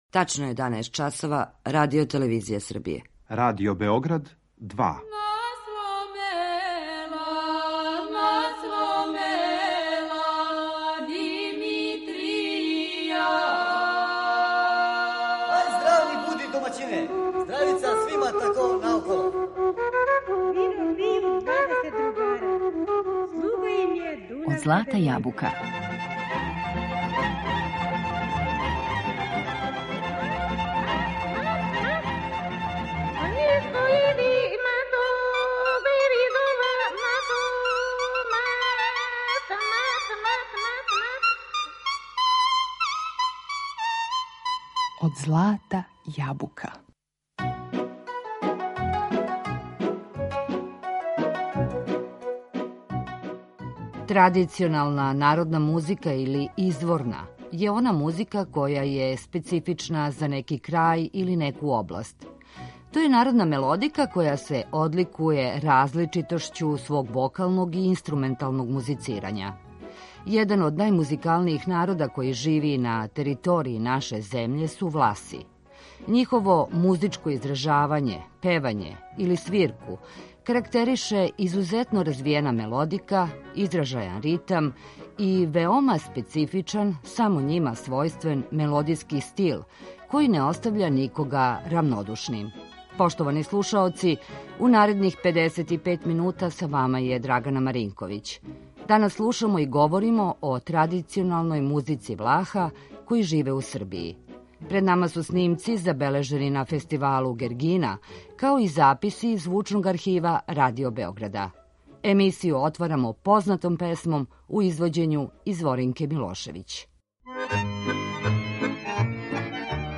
Њихово музичко изражавање, певање и свирање карактерише изузетно развијена мелодика, изражајан ритам и веома специфичан, само њима својствен мелодијски стил, који никога не оставља равнодушним. Данас говоримо о традиционалној музици Влаха који живе у Србији. Причу ћемо обојити музиком која је забележена на Фестивалу „Гергина" у Неготину и снимцима из Звучног архива Радио Београда.